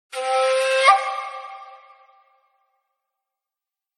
Panpipe.ogg